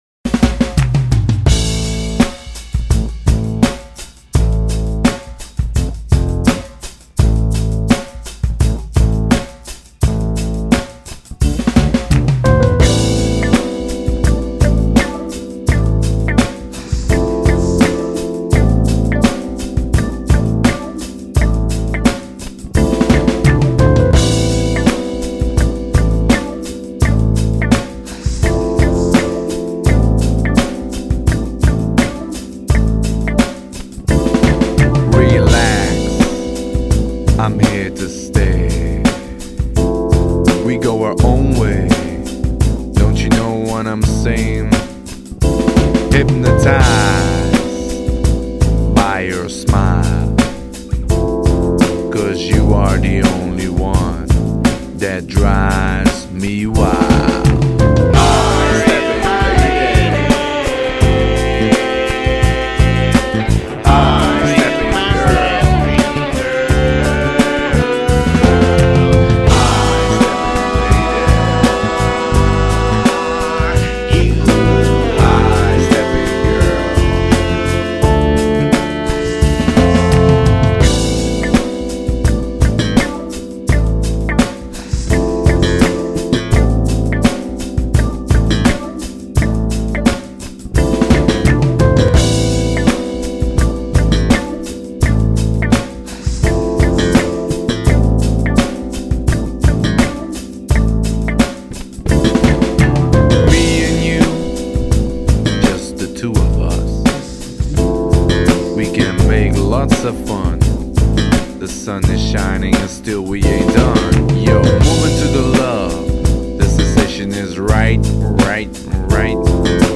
Lead vocals
Backing vocals